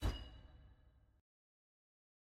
sfx-uikit-arena-modal-hover.ogg